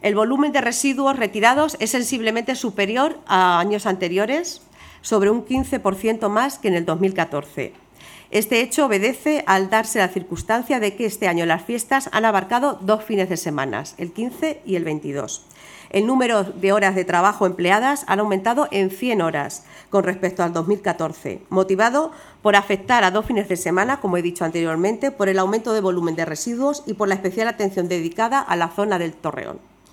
Manela Nieto, concejala de festejos (2)